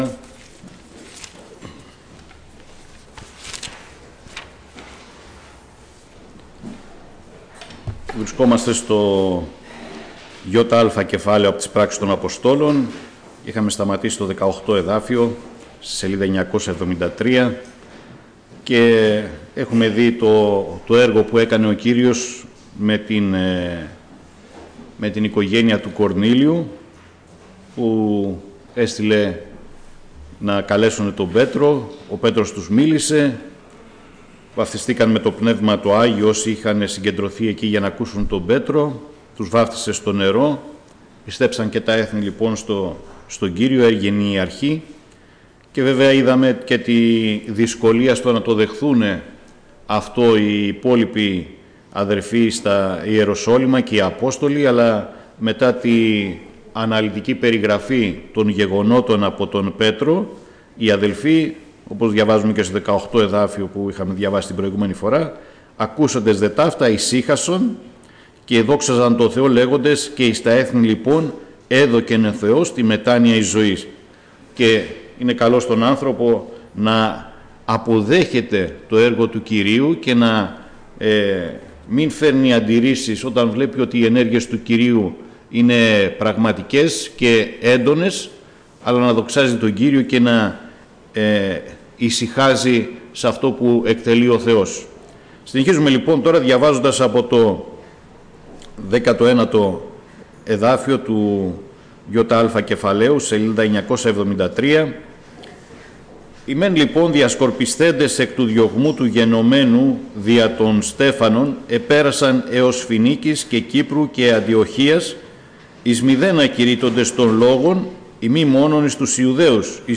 Ομιλητής: Διάφοροι Ομιλητές